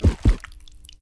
HeartSnd.ogg